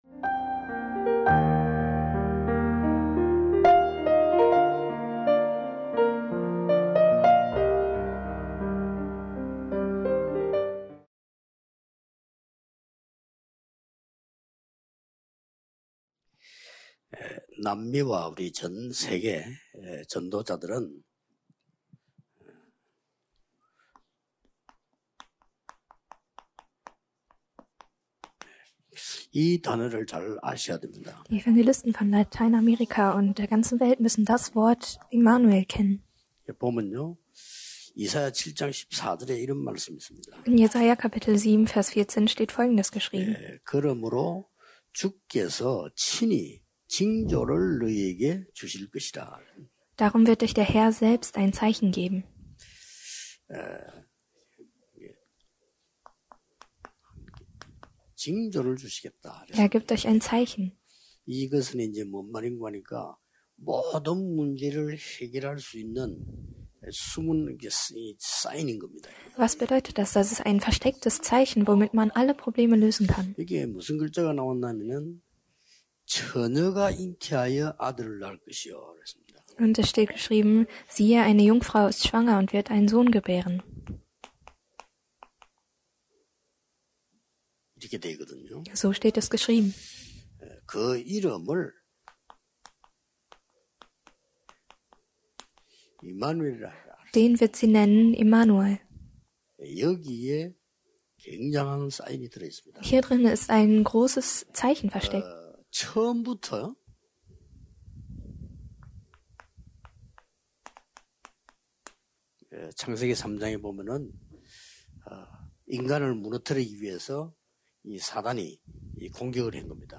19.03.2022 Kernpredigt - 「Der Genuss von Immanuel」 (Jes 7:14)